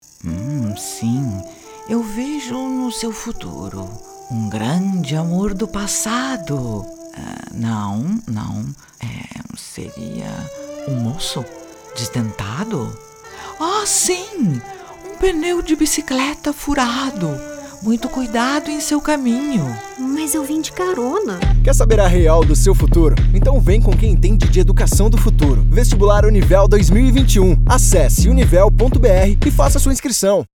Feminino
Voz Caricata 00:30